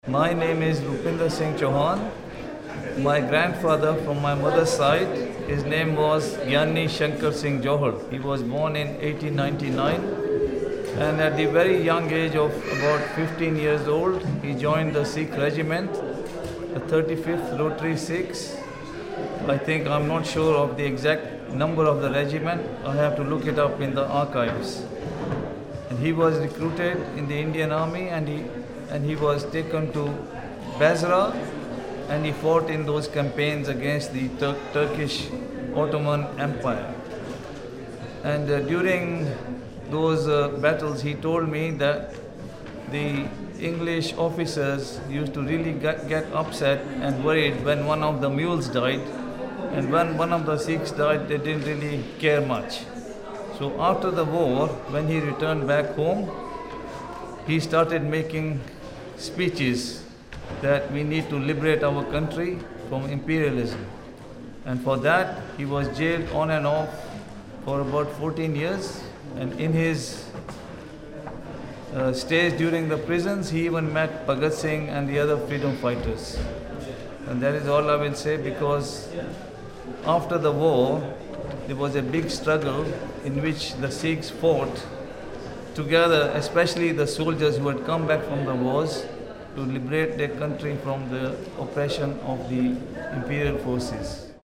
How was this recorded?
Location: Brunei Gallery, London